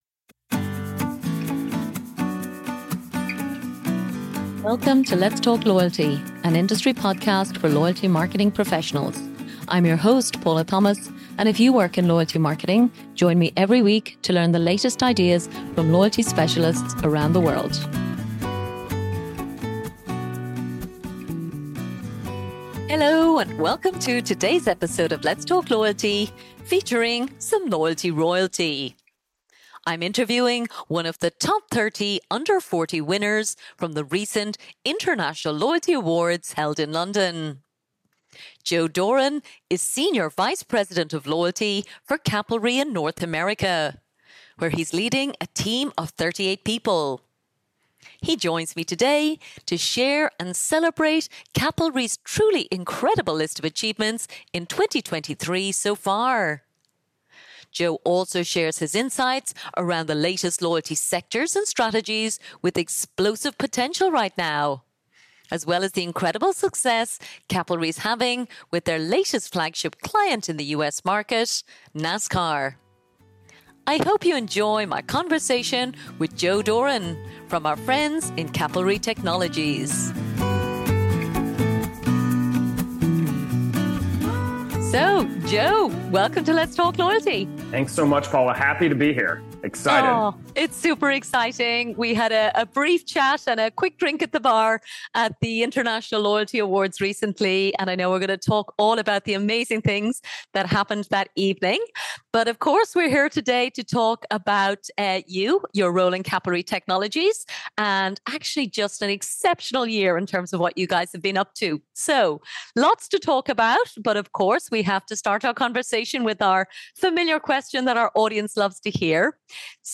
Today’s episode features some "Loyalty Royalty" an interview with one of the 2023 “Top 30 under 40” award winners at the recent International Loyalty Awards in London.